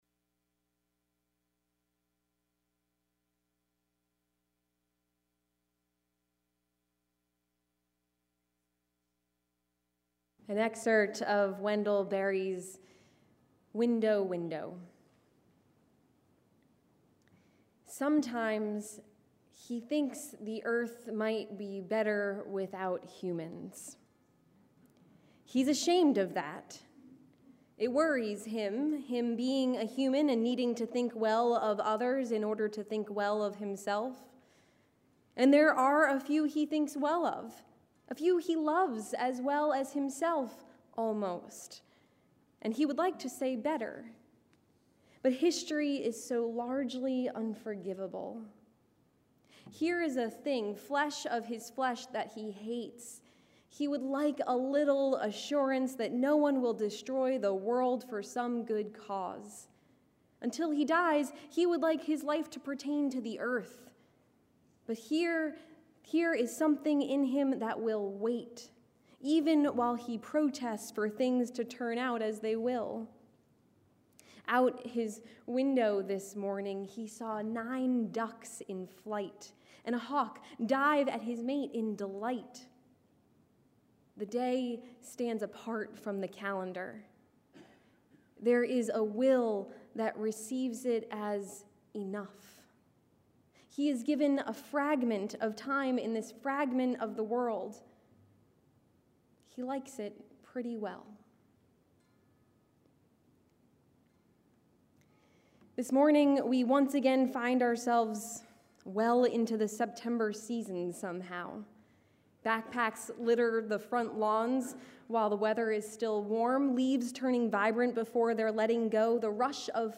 Join us for a day of song and word.